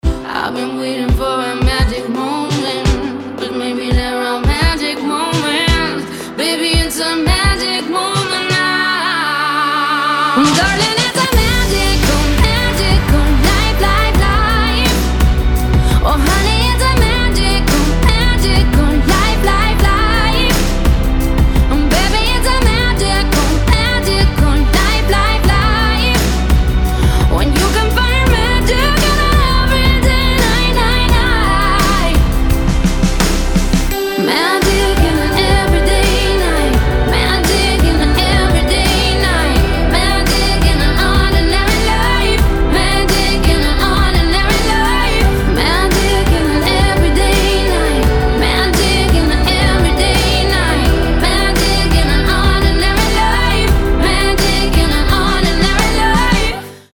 • Качество: 320, Stereo
поп
романтичные
красивый женский голос